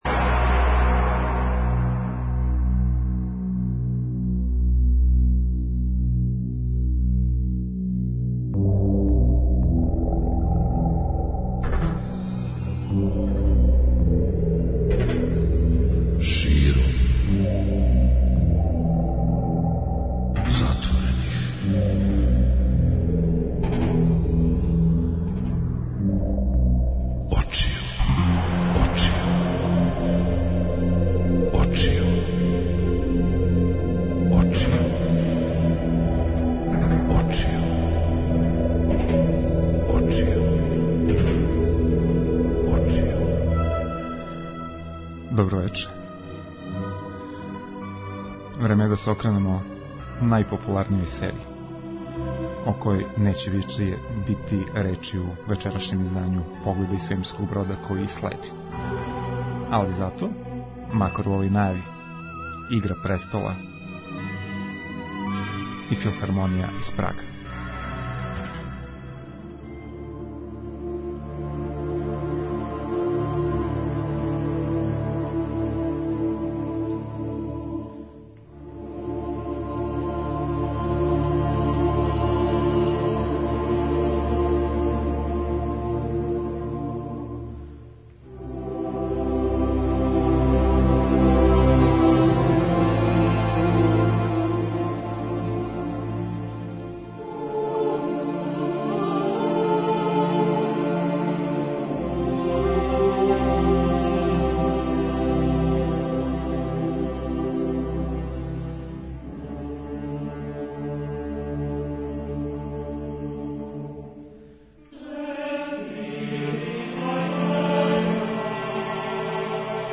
Били смо и на невероватно забавном квизу/такмичењу "Мој филм је бољи од твог" па ћете чути како су се његови учесници снашли у осмишљавању домаћег филма у којем би имали вампире, а стижу и аудио-снимци са трибине на којој се разговарало о "Чудесној жени", коју је, као књигу, објавила издавачка кућа Урбан ридс.
За крај емисије чућете нове песме домаћих бендова који су их представили таман за Ноћ вештица.